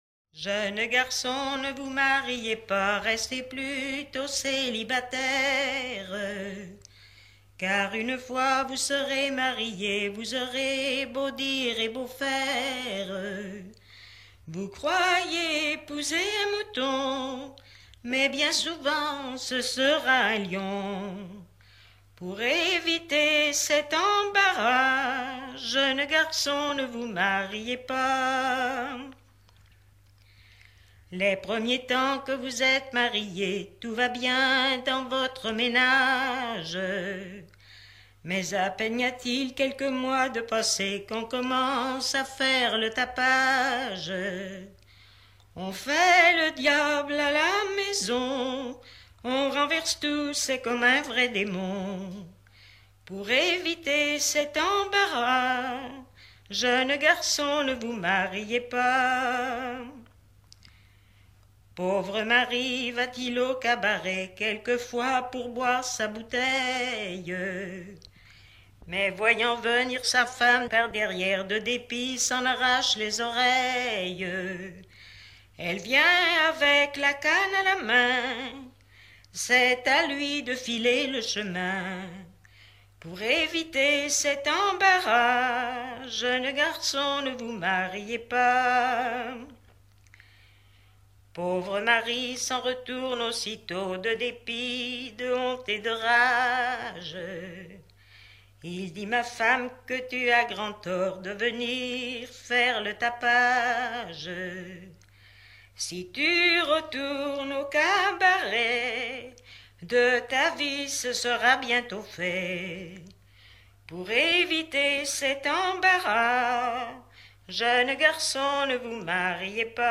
Genre dialogue
Pièce musicale éditée